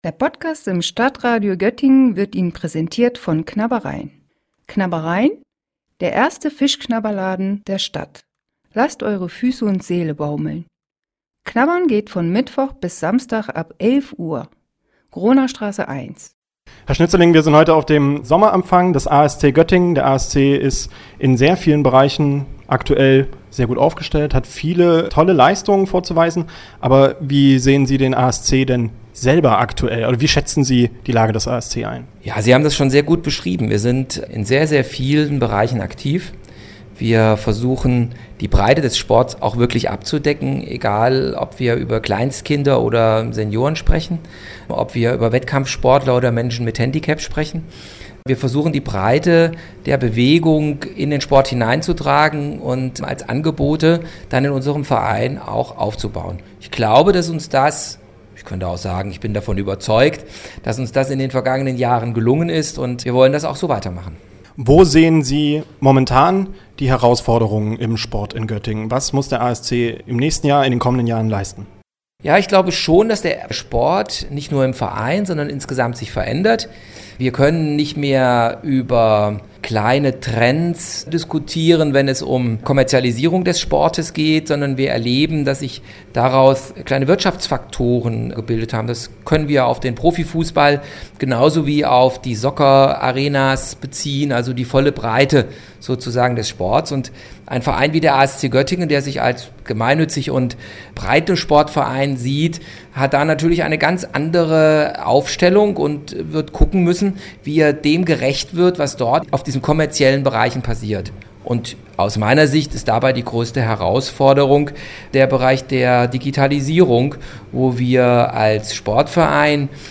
Beiträge > ASC Sommerfest 2019 im ASC Clubhaus - StadtRadio Göttingen